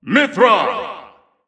The announcer saying Mythra's name in English releases of Super Smash Bros. Ultimate.
Mythra_English_Announcer_SSBU.wav